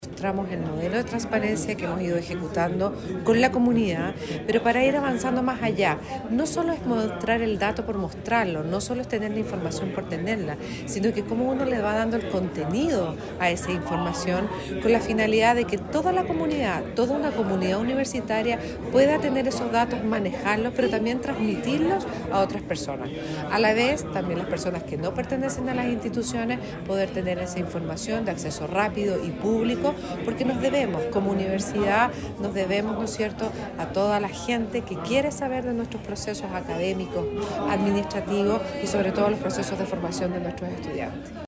Con una fuerte apuesta por el fortalecimiento de la confianza pública y el acceso abierto a la información, las universidades agrupadas en la Red G9 celebraron el pasado viernes 28 de marzo el seminario “Transparencia en Educación Superior: avances y desafíos” en la Unidad de Santiago de la Universidad de Concepción (UdeC).